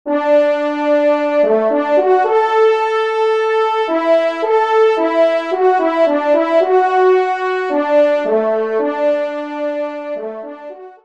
Genre : Divertissement pour Trompes ou Cors
Pupitre 1° Trompe